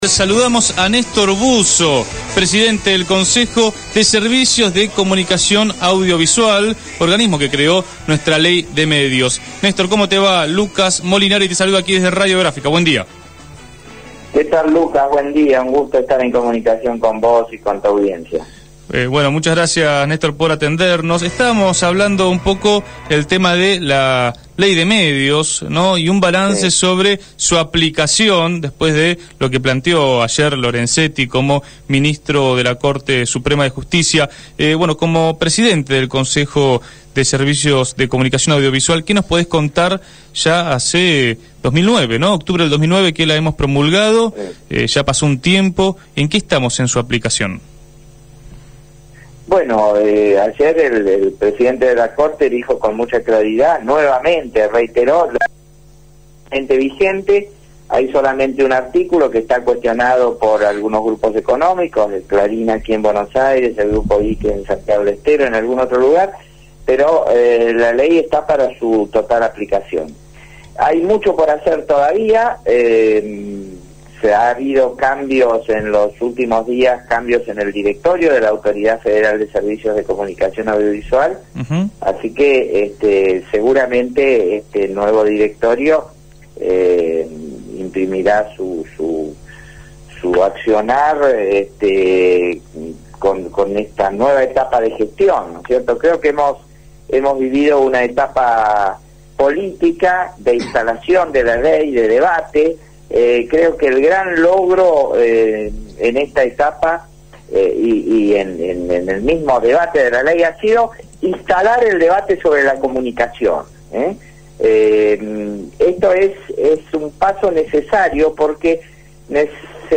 Néstor Busso, presidente del Consejo Federal de Comunicación Audiovisual, habló con Punto de Partida.